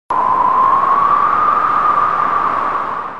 刮风的声音 风声音效
【简介】： 刮风的声音，风的音效